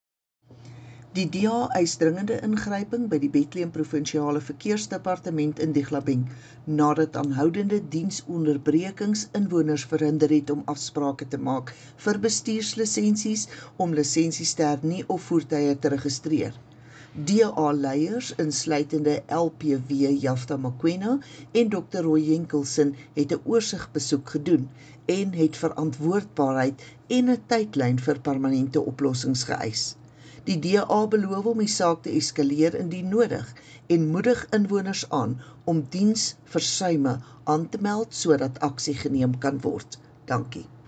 Afrikaans soundbites by Cllr Estie Senekal and